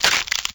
NeckSnap2.ogg